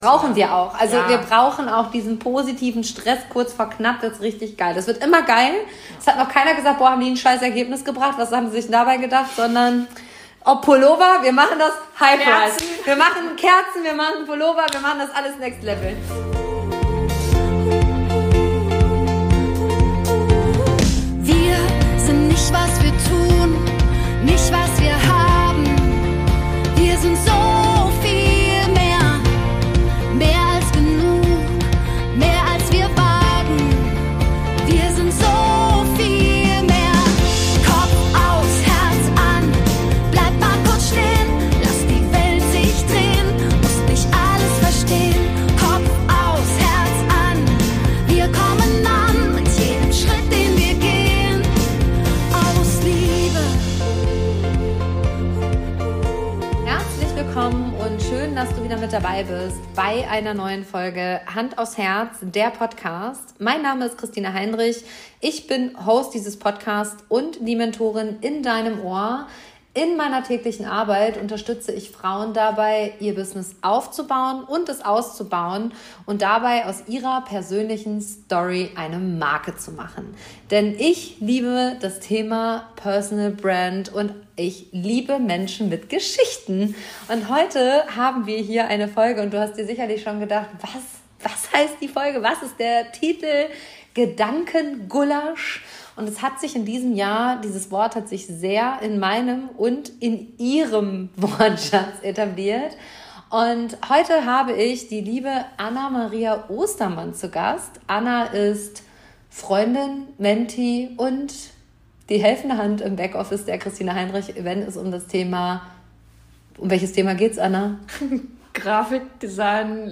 Gemeinsam tauchen wir ein in ein ehrliches und inspirierendes Gespräch voller Gedanken, Träume und Reflexionen.